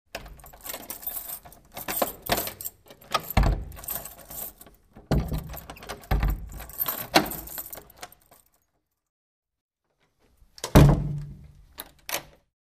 Звуки поворота ключа
Открываем кабинетную дверь ключом